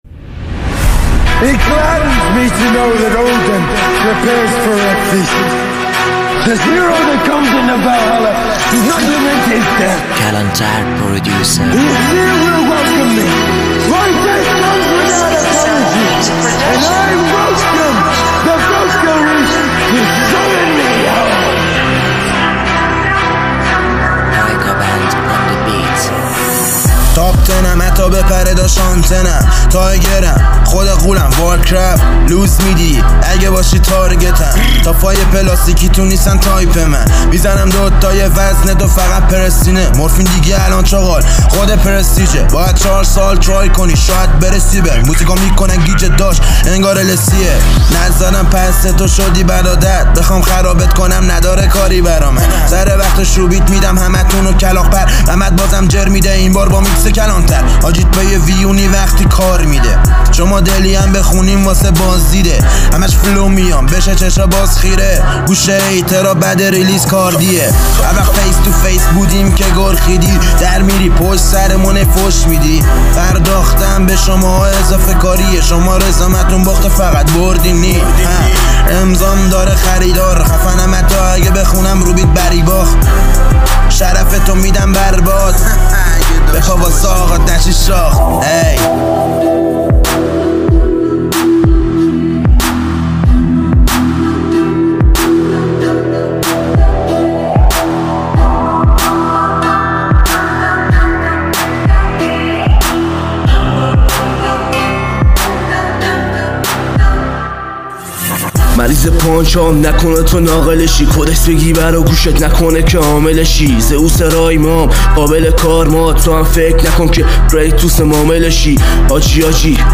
اهنگ رپ حماسی هیپ هاپ